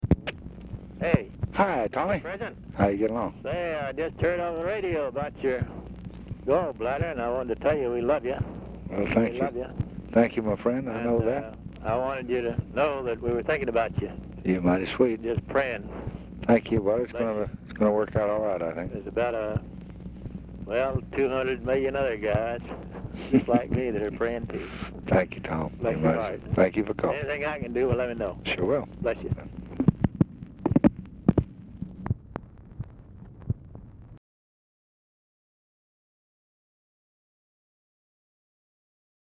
Telephone conversation
Format Dictation belt
Location Of Speaker 1 Oval Office or unknown location